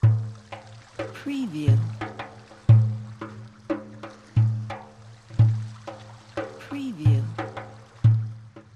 سمپل ریتم بندیر | دانلود 340 ریتم آماده بندیر
سمپل ریتم بندیر | مجموعه کامل از ریتم های ساز بندیر با کیفیت فوق العاده بالا و نوازندگی حرفه ای در ریتم های 3/4 - 4/4 - 6/8
demo-bendir.mp3